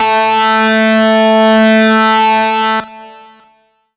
gtdTTE67004guitar-A.wav